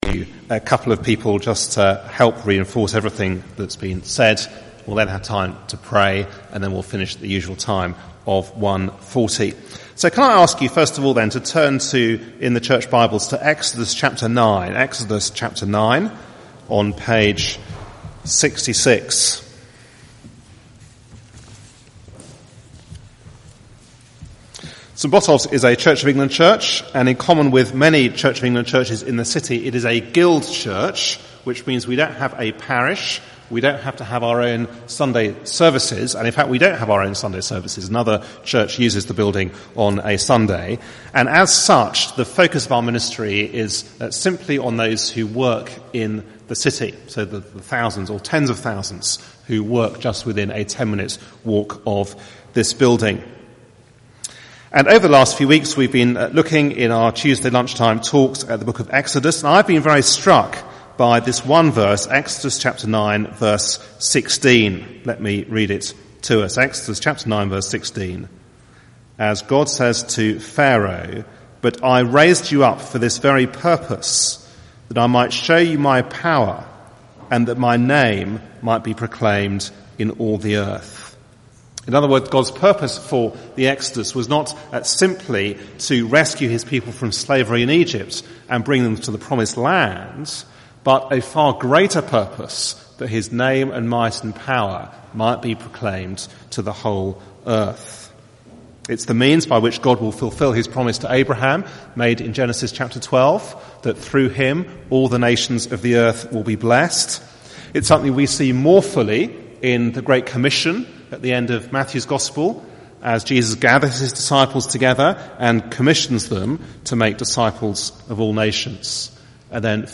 Church Partnership Day- recorded at The Aldersgate Talks ()
given at a Wednesday meeting